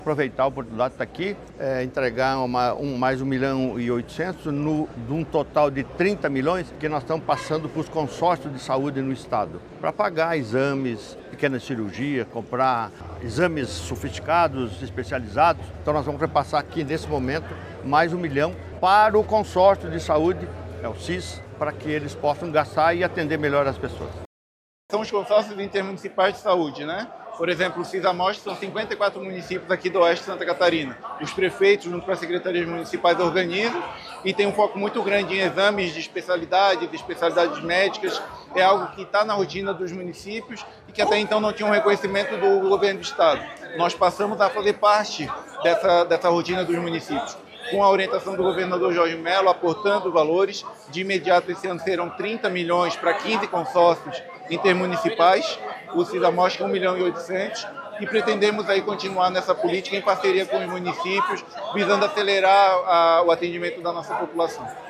O anúncio foi feito durante agenda na cidade de Chapecó nesta terça-feira, 22.
O governador Jorginho Mello lembra que a saúde dos catarinenses é prioridade em sua gestão:
SECOM-Sonoras-Repasse-Consorcio-Saude.mp3